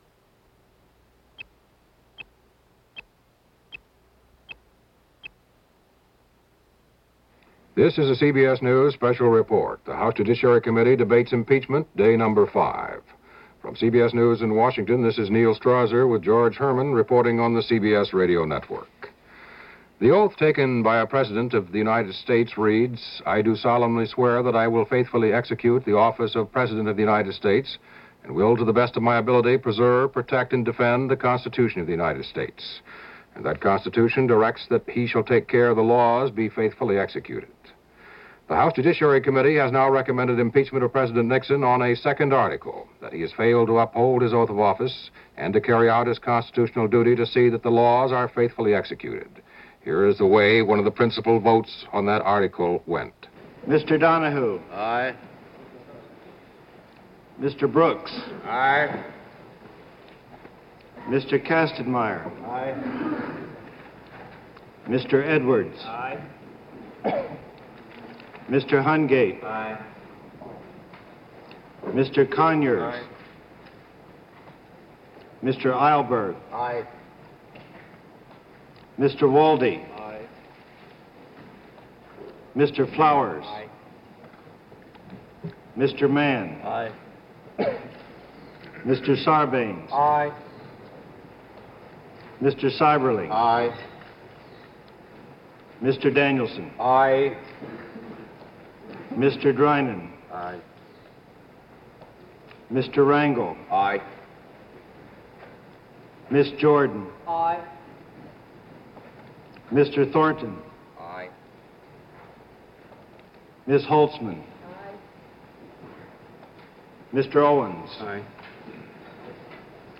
Following the introduction, this broadcast, a regular feature during the Watergate Hearings, runs down the votes as the second article ends up being passed.
Here is that vote from July 29, 1974 as broadcast in a Special Report by CBS Radio.